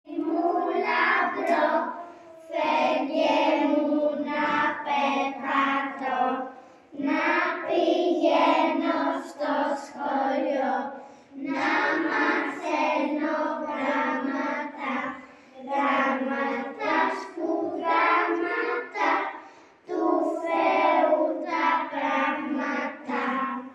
Παράλληλα κάνουμε πρόβα για τη γιορτή.